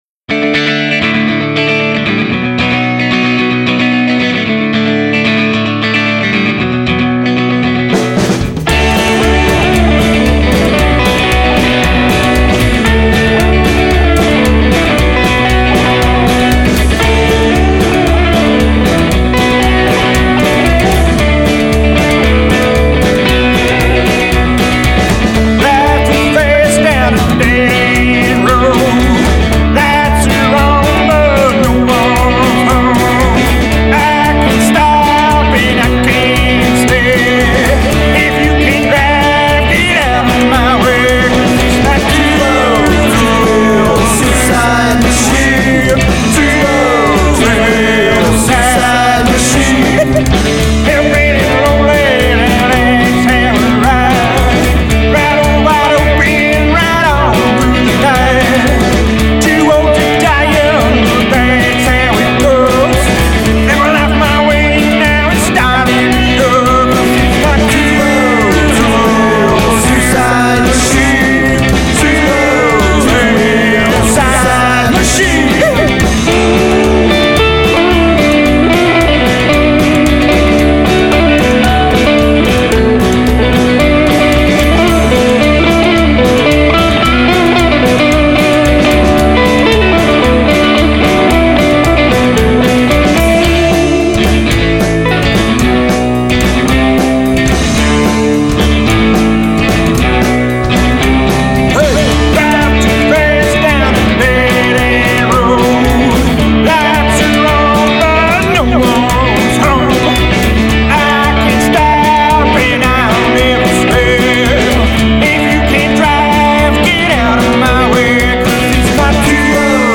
high octane offering!